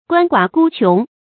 鳏寡孤茕 guān guǎ gū qióng 成语解释 茕，无兄弟。泛指没有劳动力而又没有亲属供养的人。